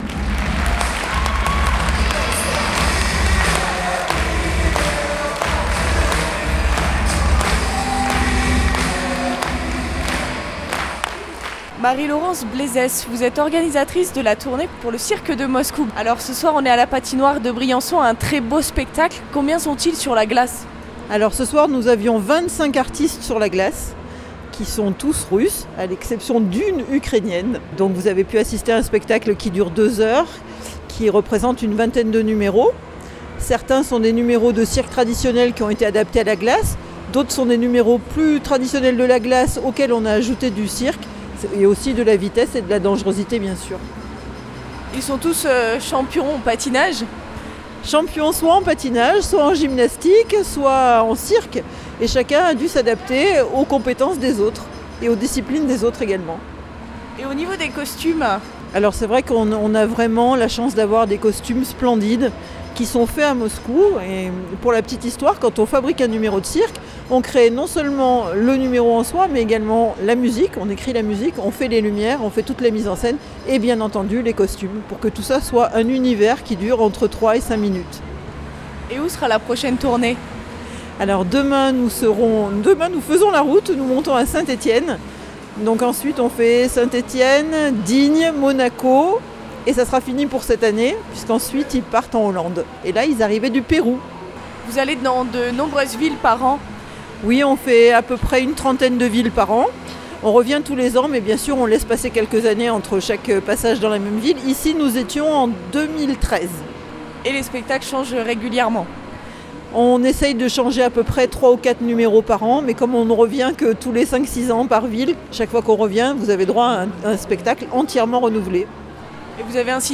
des spectateurs ravis à l’issue du spectacle.